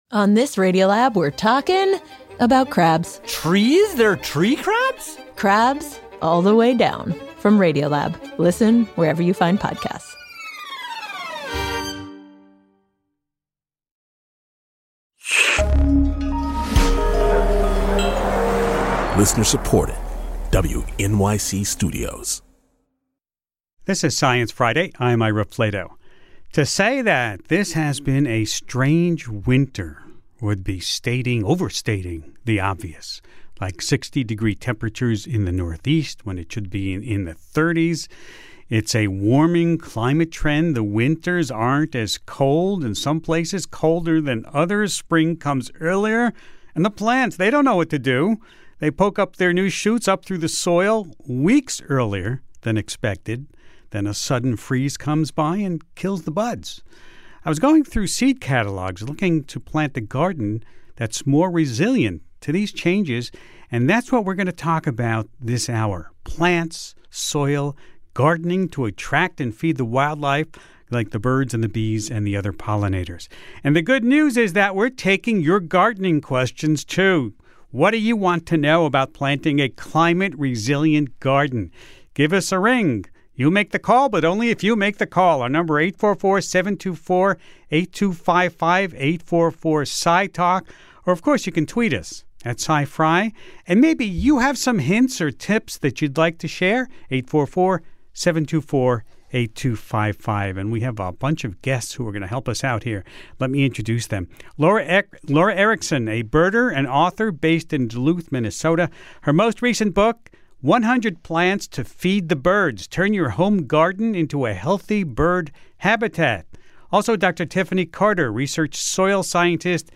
In this live show, Ira talks with a panel of guests about planting a climate-resilient garden, and how to set your plants up for success .